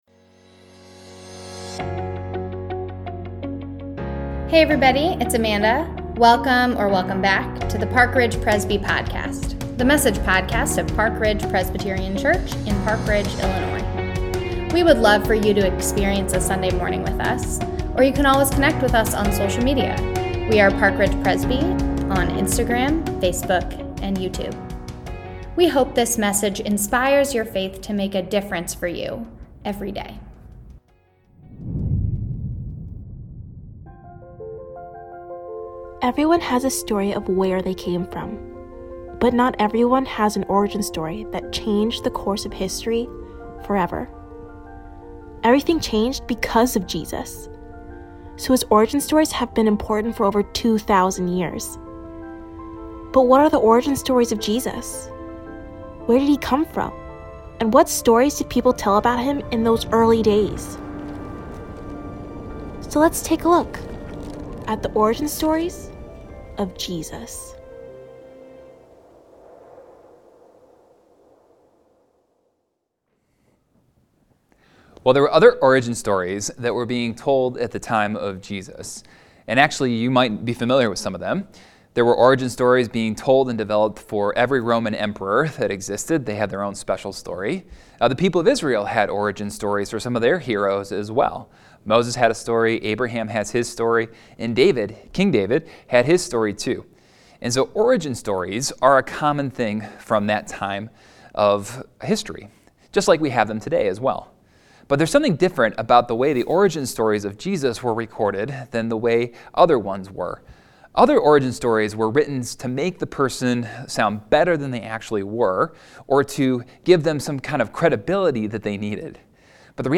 Palm Sunday | The Origin Stories of Jesus – Part 2 | Online Worship | April 10, 2022